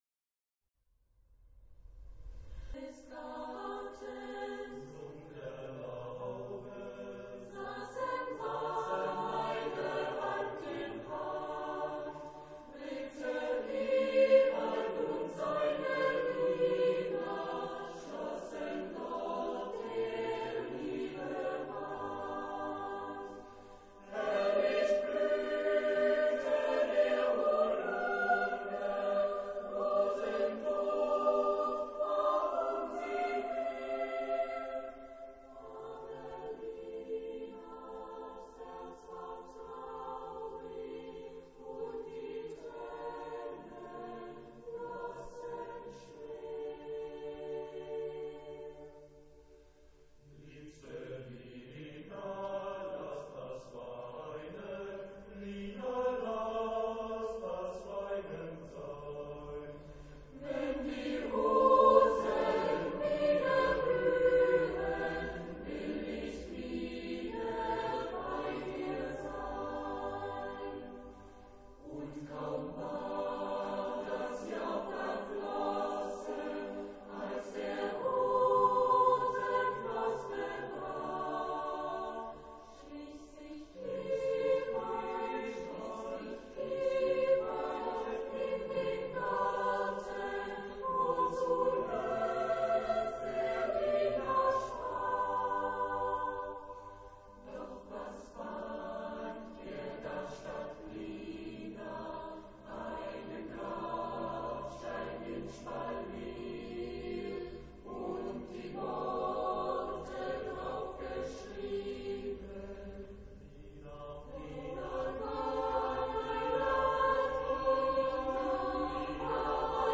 Tonalidad : sol mayor